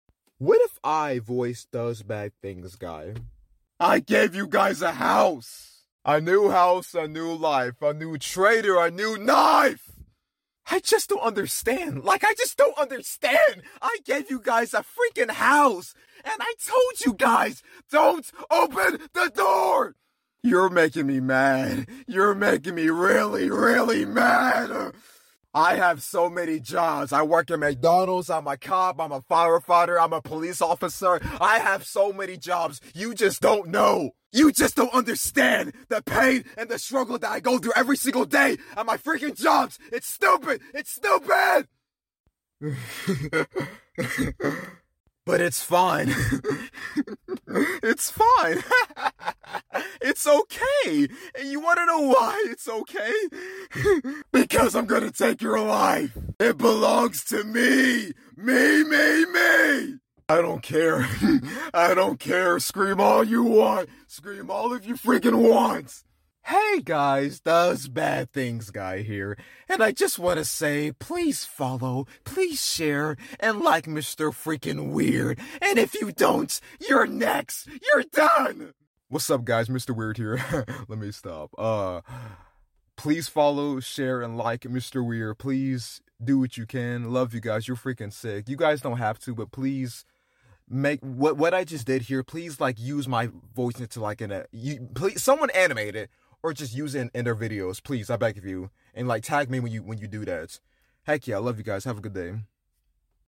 Does bad things guy voice sound effects free download
Does bad things guy voice over